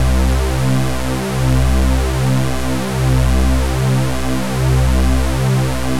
C2_jx_phat_lead_1.wav